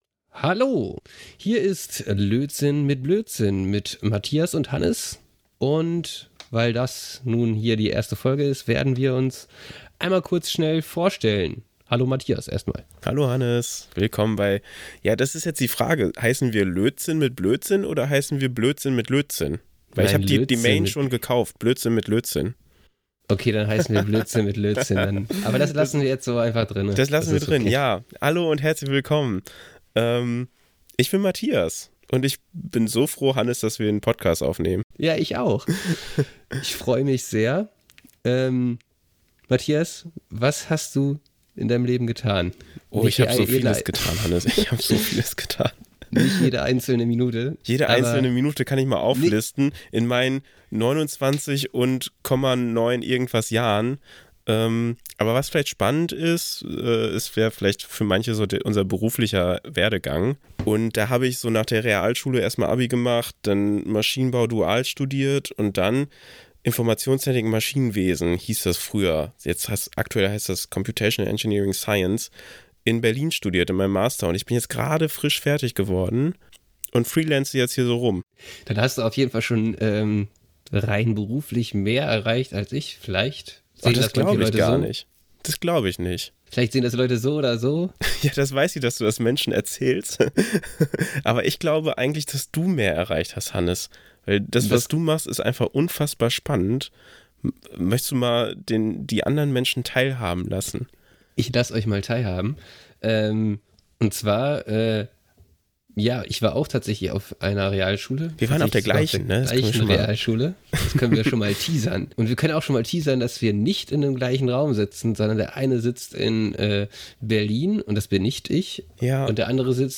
Die Episode endet mit ein paar humorvollen Outtakes, die einen Einblick in ihre Dynamik und den Spaß am Austausch geben.